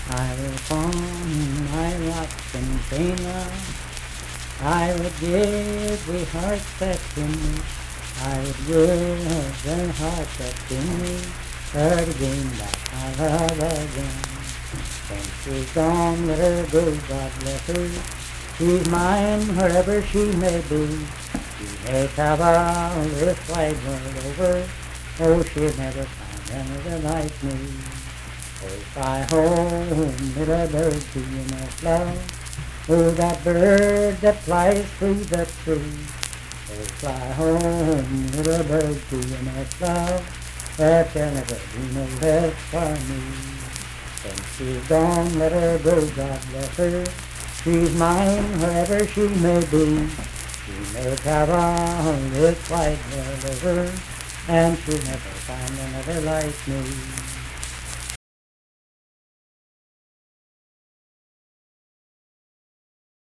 Unaccompanied vocal music performance
Verse-refrain 4(4).
Voice (sung)